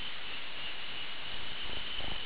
cricket.wav